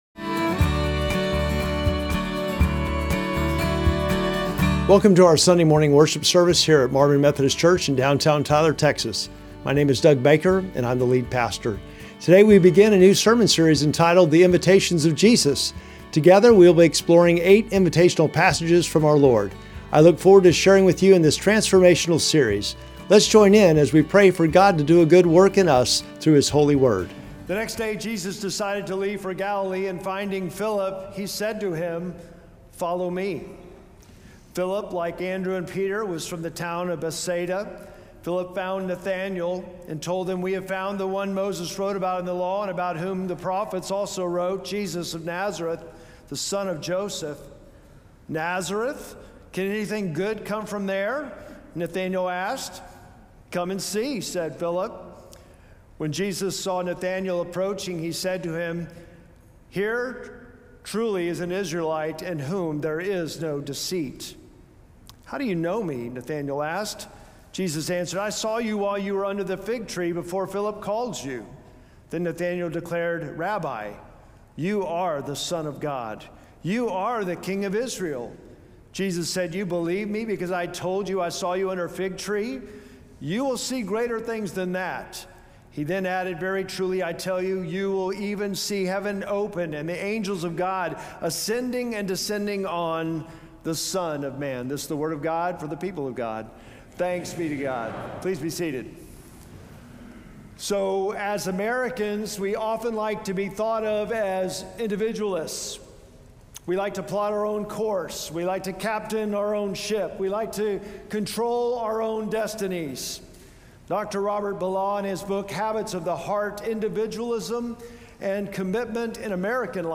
Sermon text: John 1:43-51